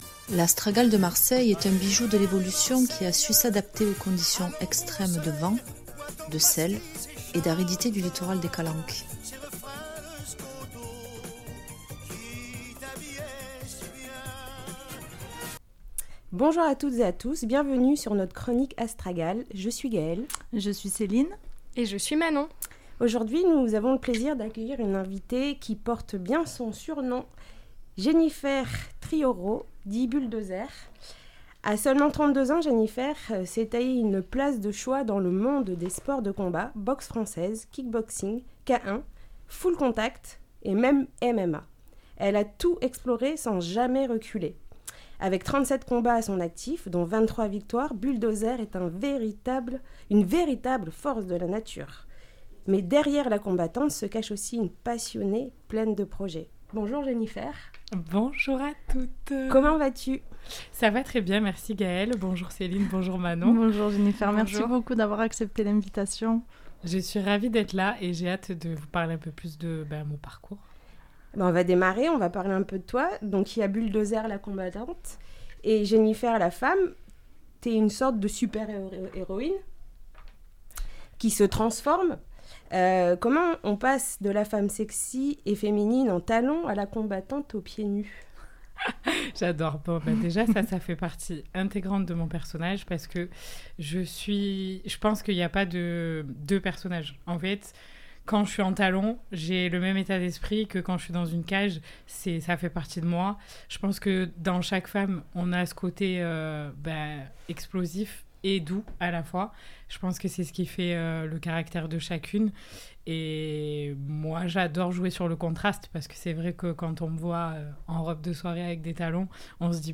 "Astragales" est une chronique mensuelle qui s'inscrit dans une volonté de valorisation de la résilience féminine et de parcours de vie des femmes qui « font » Marseille. Le projet vise à donner la parole aux femmes marseillaises aux parcours de vie variés (socialement, culturellement, professionnellement).